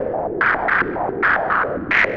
Index of /musicradar/rhythmic-inspiration-samples/110bpm
RI_RhythNoise_110-01.wav